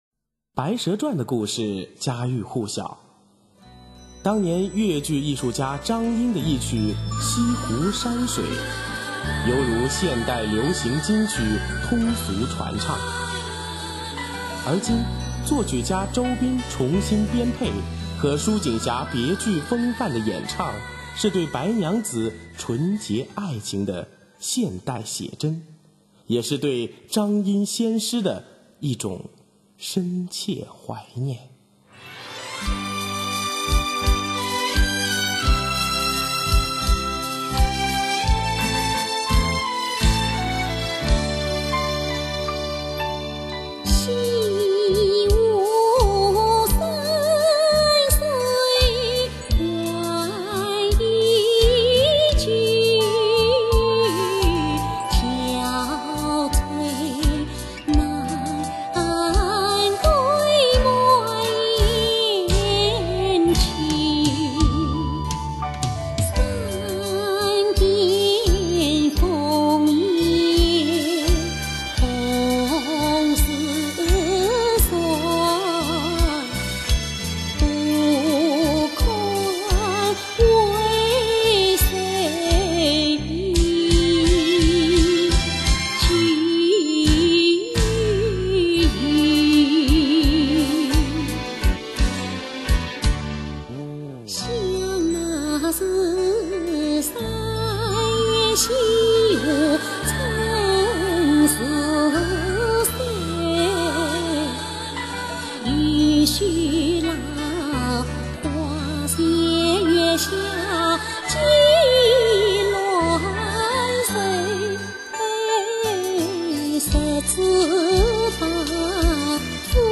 她的唱腔委婉抒情，韵味浓郁，表演真切细腻，清丽多姿，富有激情。